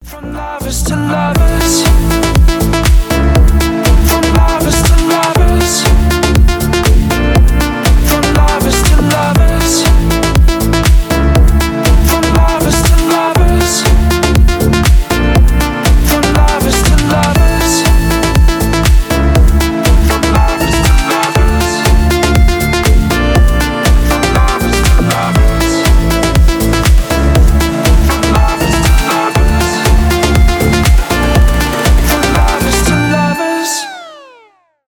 club house